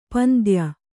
♪ pandya